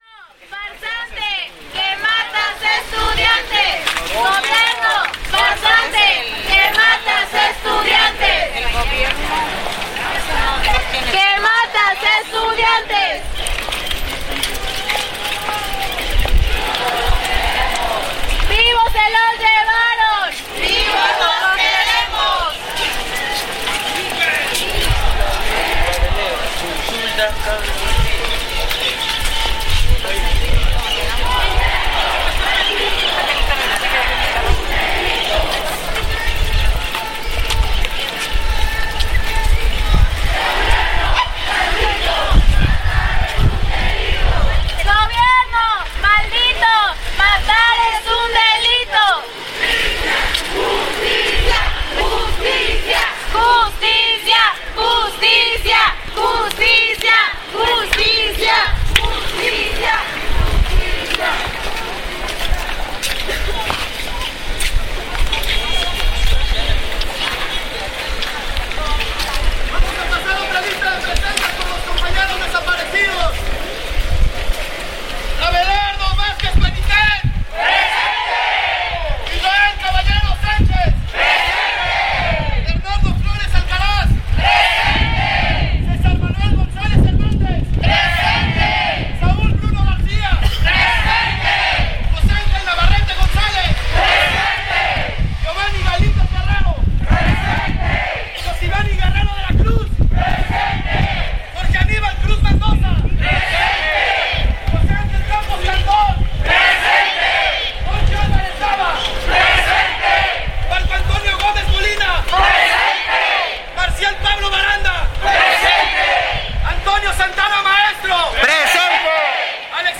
Recorrido sonoro de la marcha realizada el pasado 8 de octubre en Tuxtla Gutierrez, en protesta por los estudiantes de la Escuela Normal Ayotzinapa en Guerrero.
Lugar: Tuxtla Gutierrez, Chiapas Equipo: Grabadora Sony modelo IC RECORDER ICD-UX200